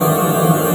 QUAKE.wav